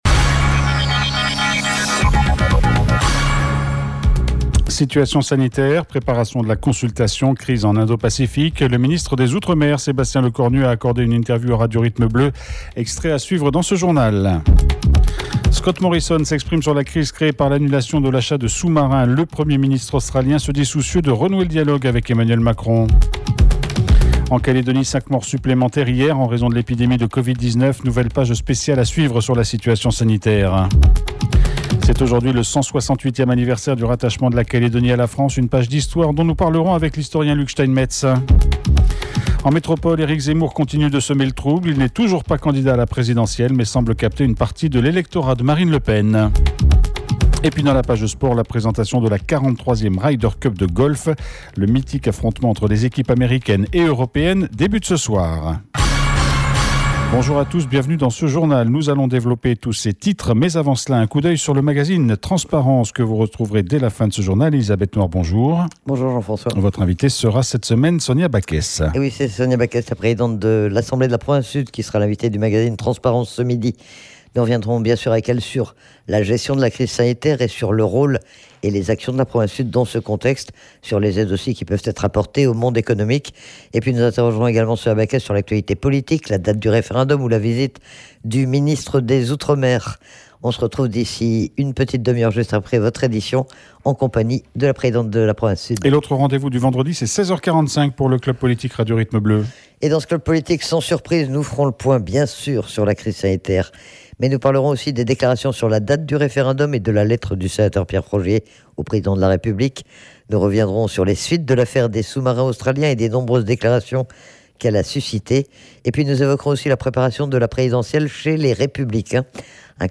JOURNAL : VENDREDI 24/09/21 (MIDI)
Sébastien Lecornu sur RRB. Le ministre des outre-mer nous a accordé un entretien, à quelques jours de sa visite en Nouvelle-Calédonie.